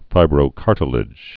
(fībrō-kärtl-ĭj)